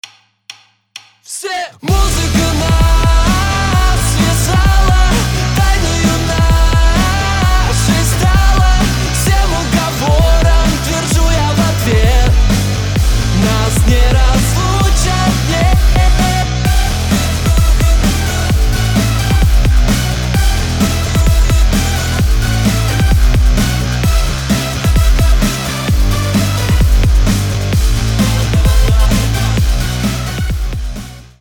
• Качество: 320, Stereo
громкие
Cover
Рок версия хита